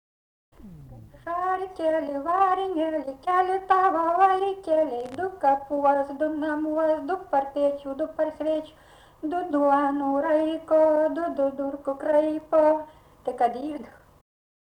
daina, vaikų
vokalinis